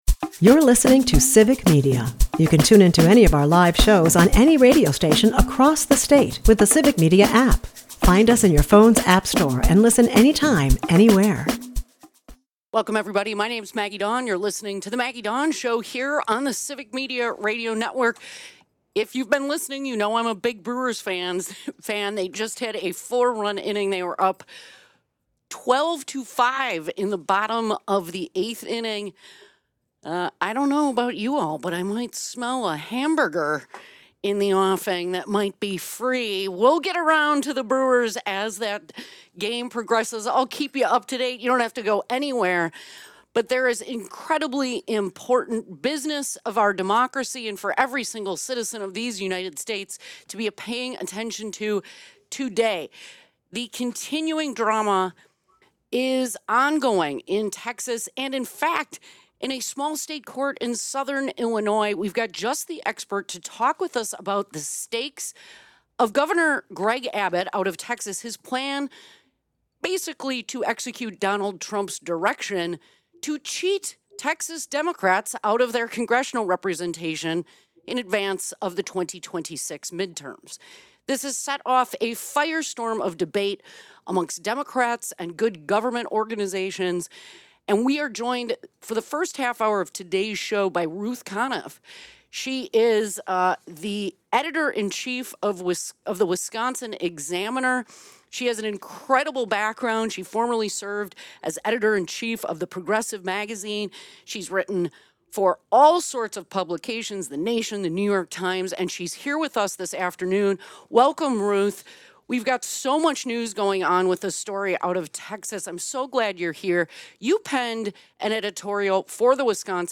Wisconsin Representative Ann Roe joins to discuss the power of listening to constituents and bridging political divides.